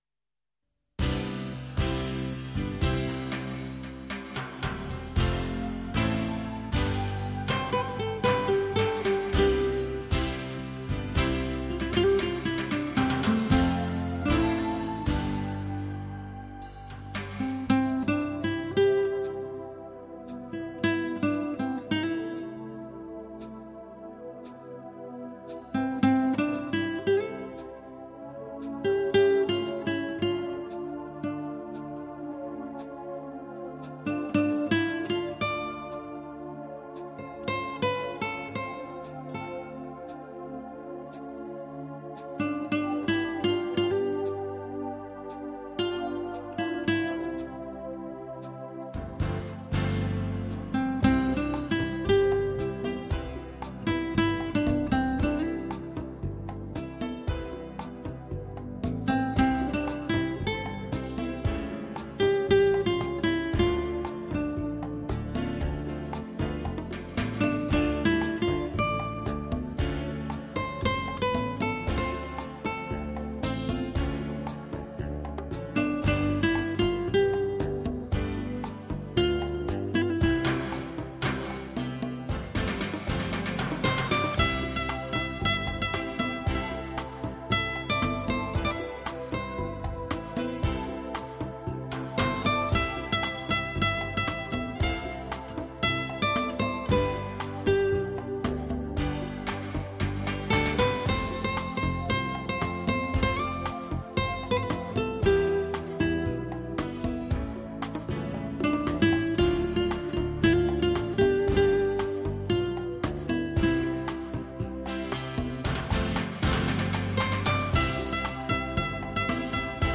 吉他演奏
西班牙吉他音乐典型的反映了拉丁民族热烈奔放的民族性格。
活力充沛，激情火辣。
专辑中的所有乐曲充满了FLAMENCO的特殊风味，可说是一道精美丰盛的西班牙大餐。